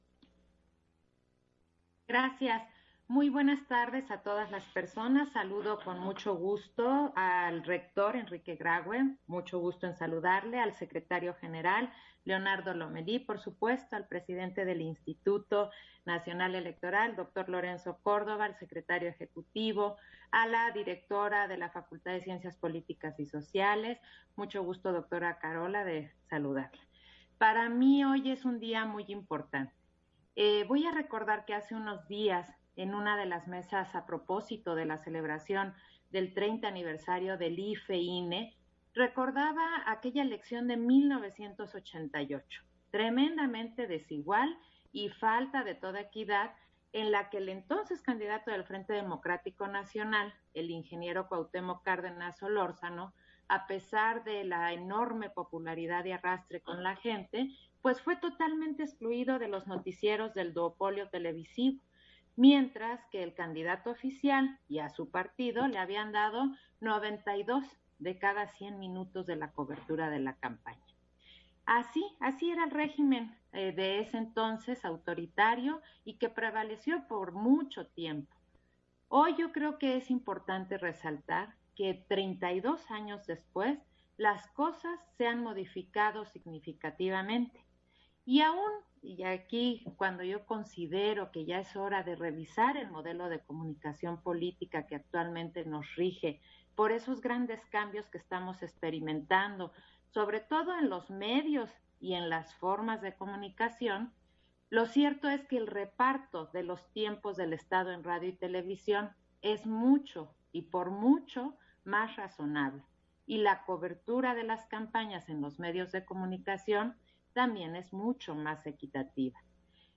Intervención de Claudia Zavala, en la firma de Convenio de Colaboración en materia de monitoreo, INE-UNAM